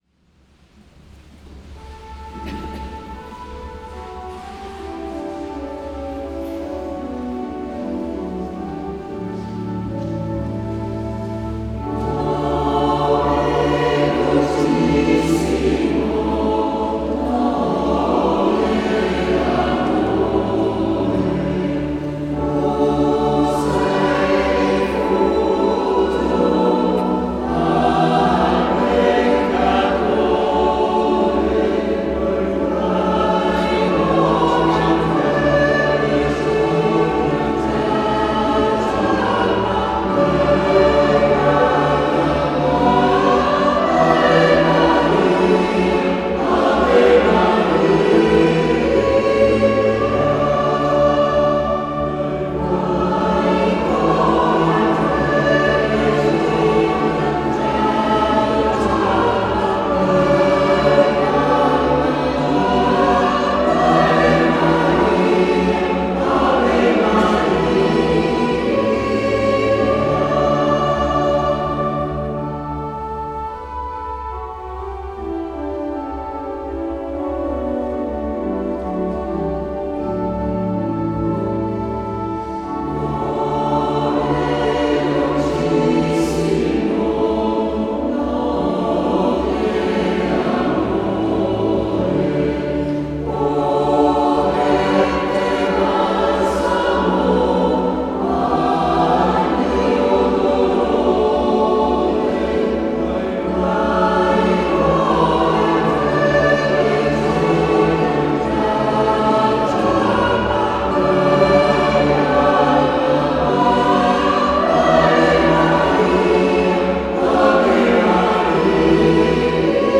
SCHOLA CANTORUM Sedico (Belluno)
Sedico 25_03_2025